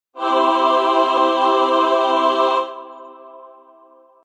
angelic-short.62451effd933b3b58713.mp3